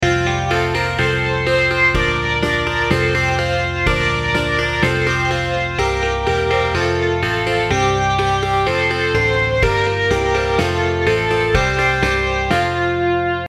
Zvuková ukázka nového nástrojového obsazení